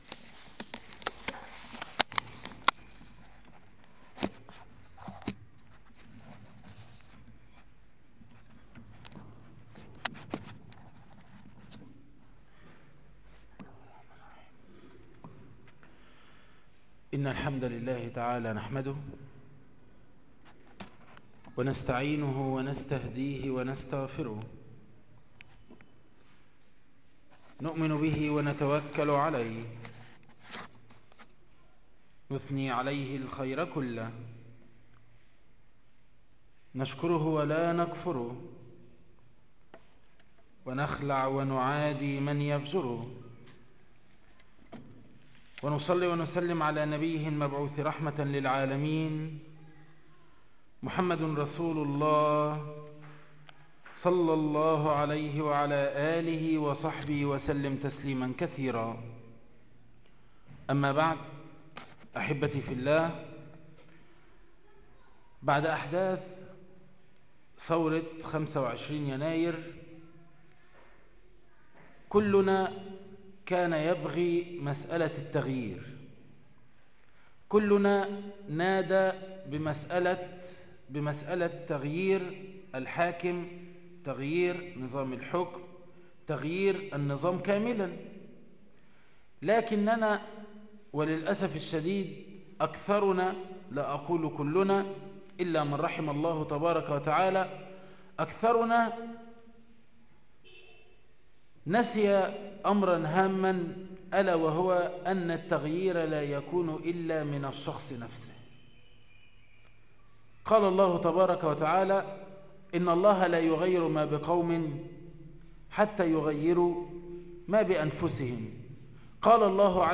عنوان المادة حق الله على العباد الدرس الأول تاريخ التحميل الثلاثاء 29 يناير 2013 مـ حجم المادة 26.27 ميجا بايت عدد الزيارات 737 زيارة عدد مرات الحفظ 259 مرة إستماع المادة حفظ المادة اضف تعليقك أرسل لصديق